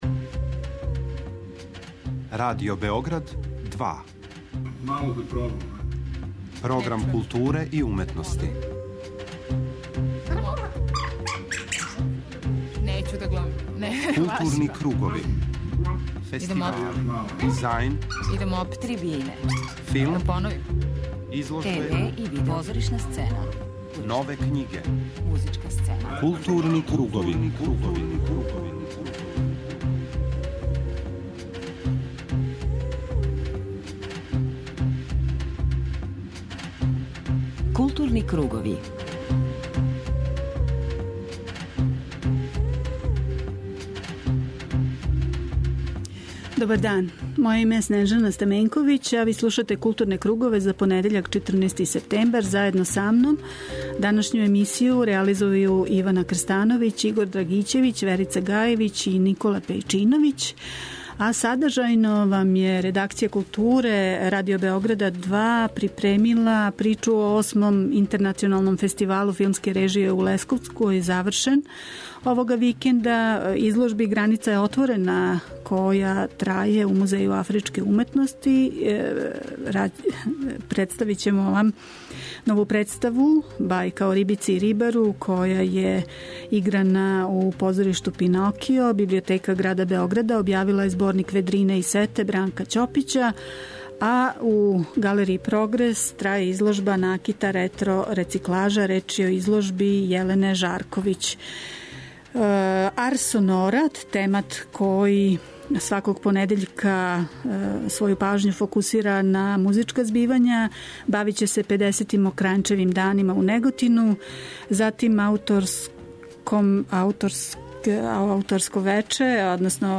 У току је педесети, јубиларни фестивал "Мокрањчеви дани", па ће слушаоци моћи да чују репортажу снимљену у Неготину о најзначајнијим догађајима који су обележили прва три фестивалска дана, а говорићемо и о концертима одржаним у Београду у оквиру "Бас фестивала".